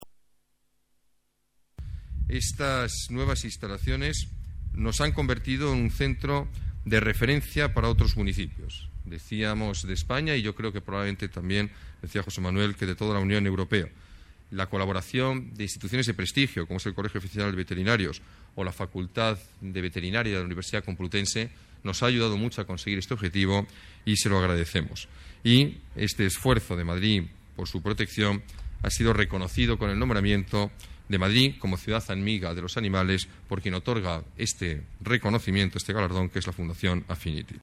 Nueva ventana:Declaraciones del alcalde de la Ciudad de Madrid, Alberto Ruiz-Gallardón: Centro de Protección Animal